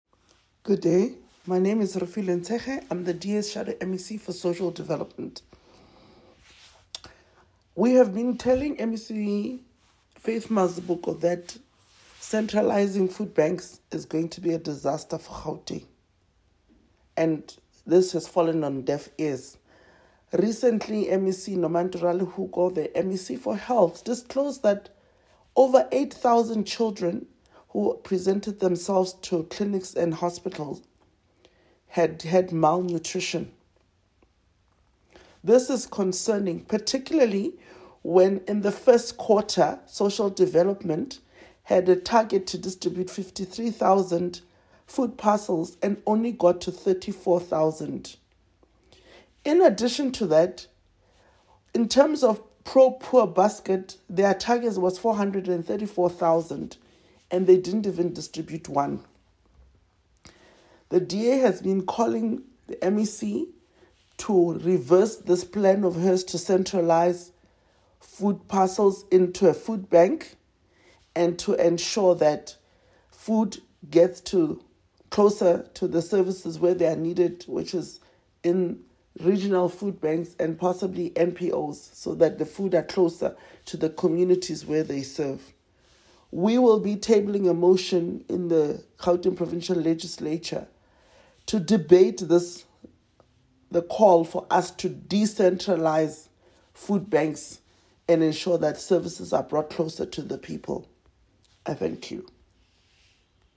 soundbite by Refiloe Nt’sekhe MPL.